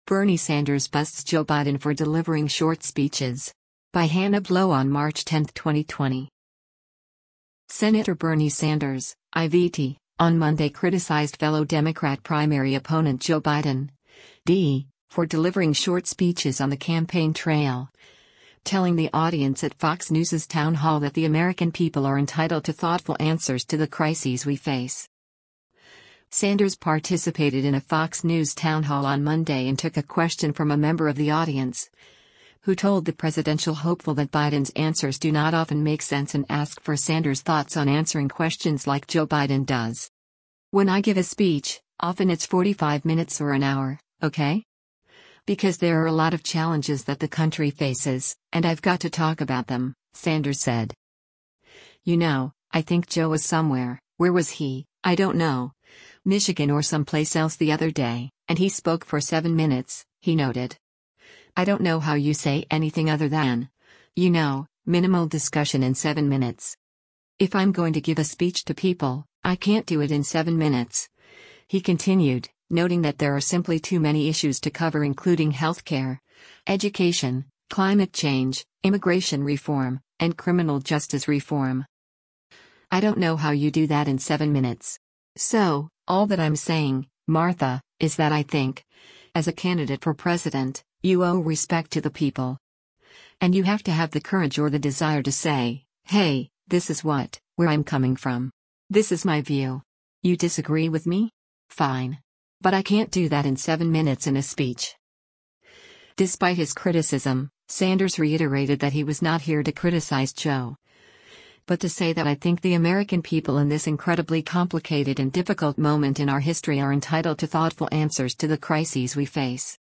Sanders participated in a Fox News town hall on Monday and took a question from a member of the audience, who told the presidential hopeful that Biden’s answers do not often “make sense” and asked for Sanders’ thoughts on answering questions “like Joe Biden does.”